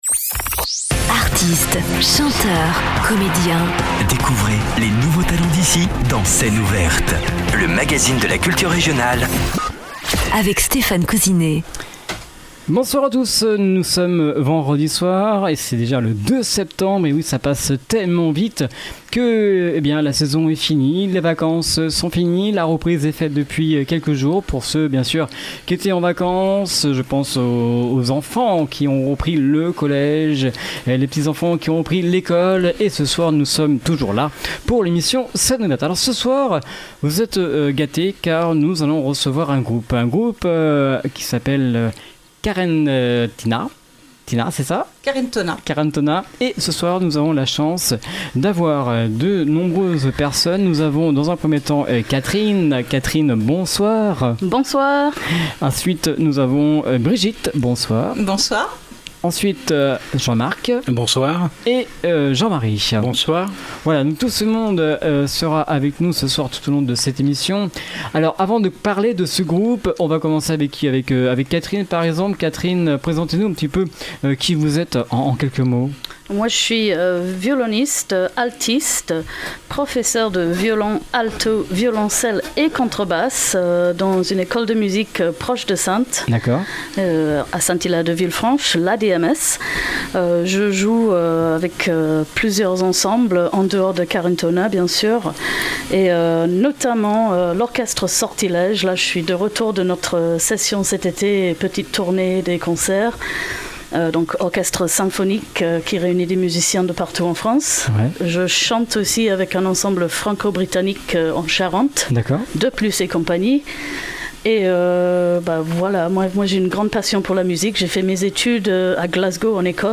musique celtique
Le groupe est actuellement composé de 4 musiciens. http